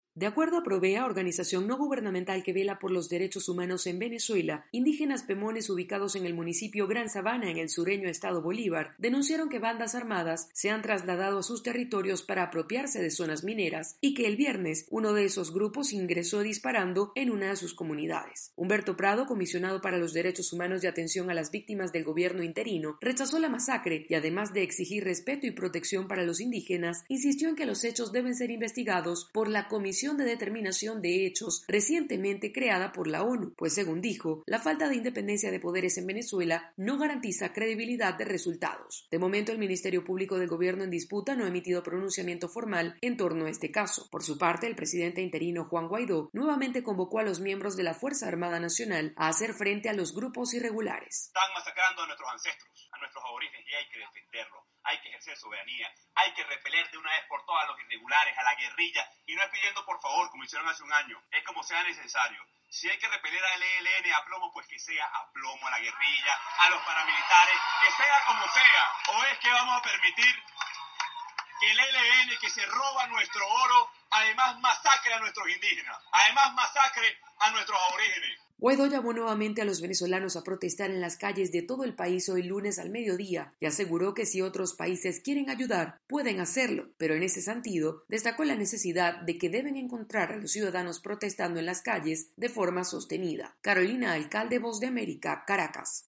VOA: Inforeme desde Venezuela